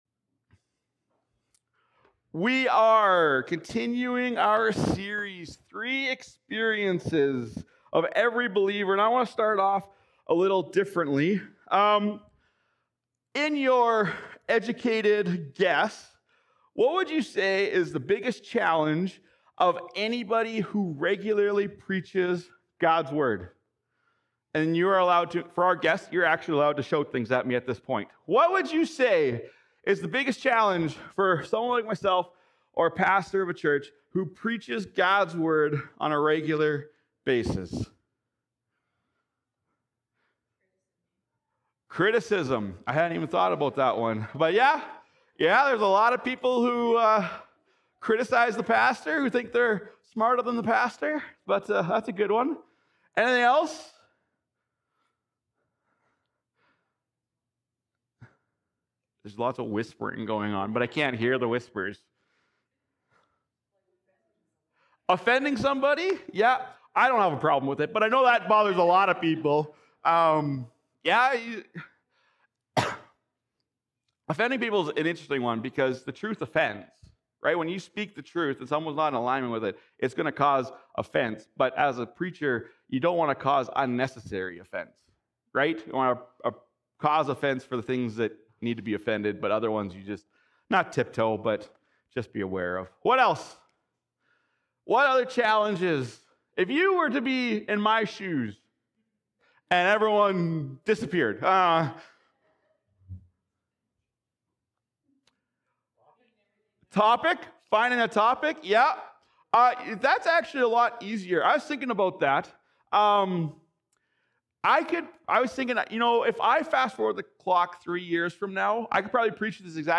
Sermons | OneChurch